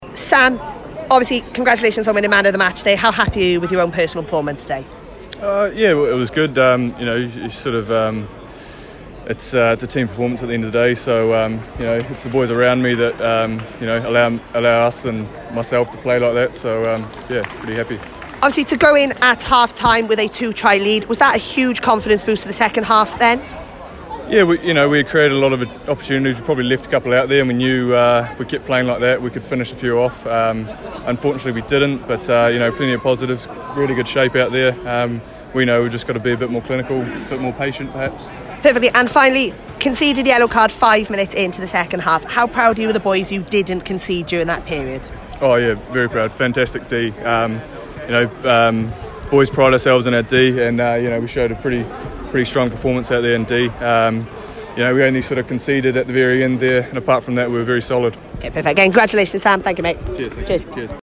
Post Match Interviews.